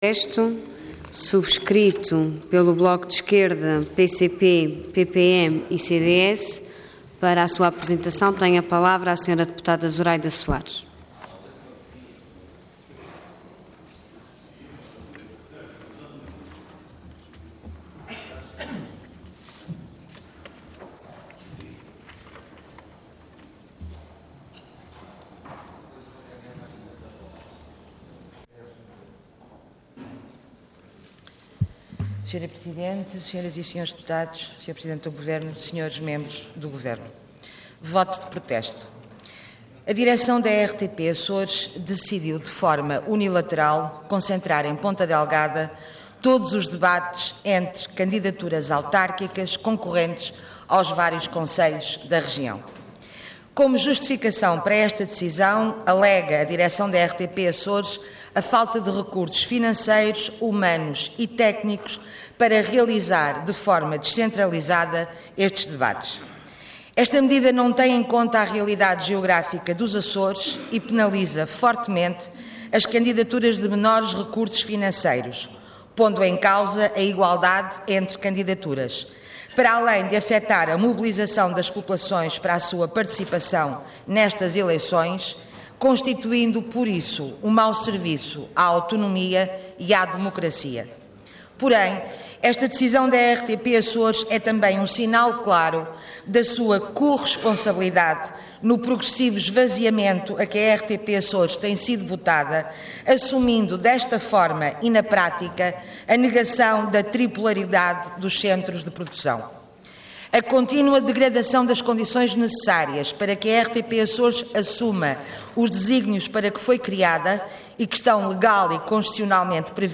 Intervenção Voto de Protesto Orador Zuraida Soares Cargo Deputada Entidade PCP